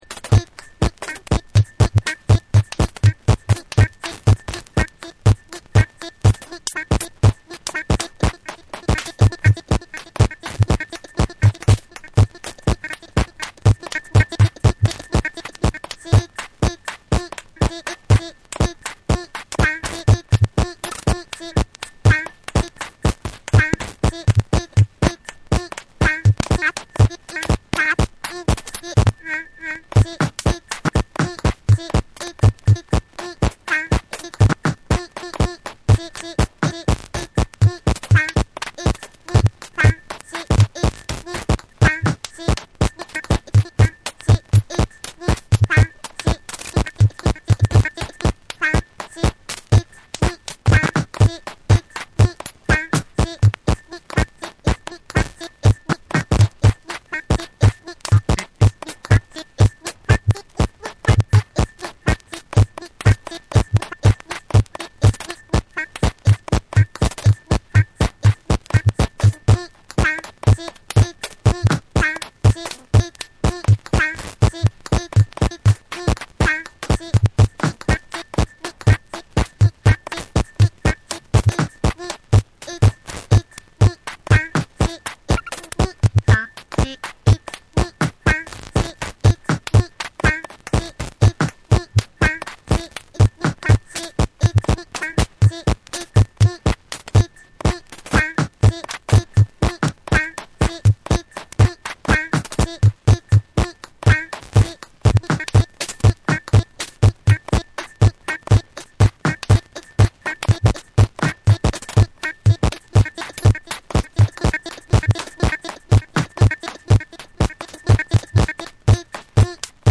硬質ながらもどこか内省的なムードを湛えた、摩訶不思議でユーモラスな雰囲気も伺えるトラックを収録。
JAPANESE / BREAKBEATS